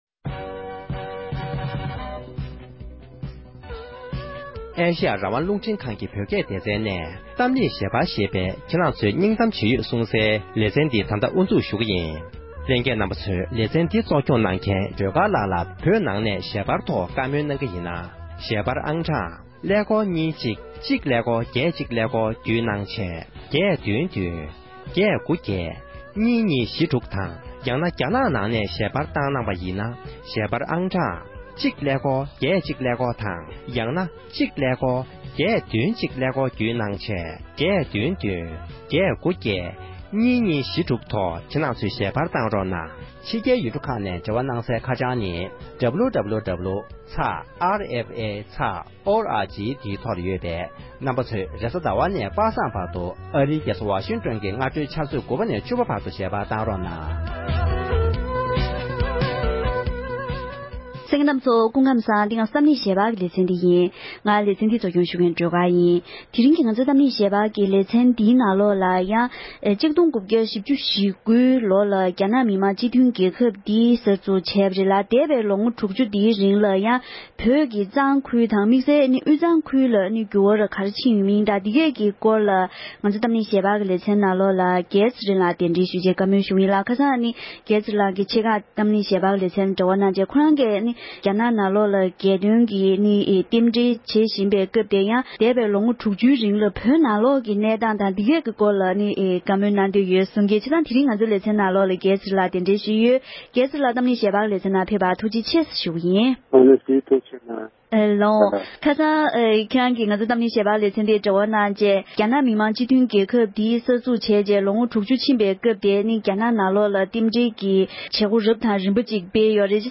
འདས་པའི་ལོ་ངོ་ལྔ་བཅུའི་རིང་གི་བོད་ཀྱི་རྒྱལ་རྩེ་ས་ཁུལ་གྱི་དུས་ཀྱི་འགྱུར་བ་དང་བོད་མིའི་ཁྲོད་འཕྲད་པའི་དཀའ་སྡུག་སྐོར་གྱི་བགྲོ་གླེང༌།